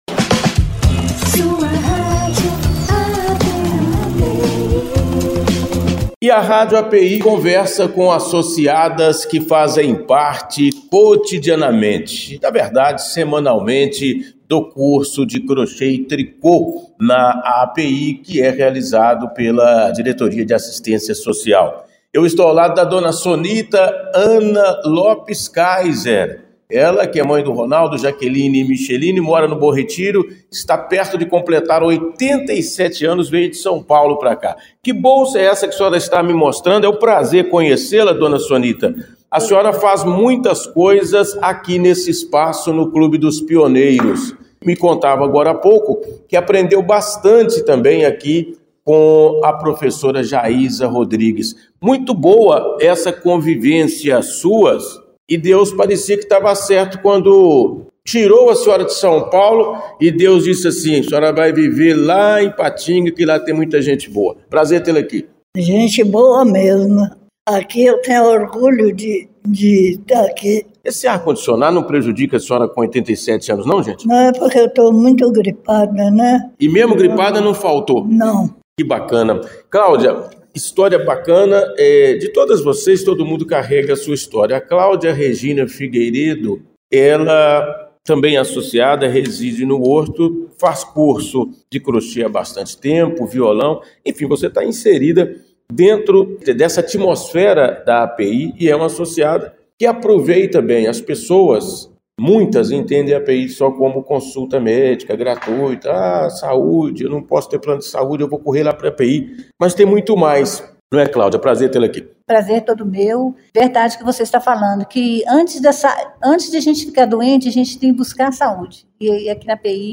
Radio-AAPI-entrevista-associadas-dos-cursos-de-trico-e-croche.mp3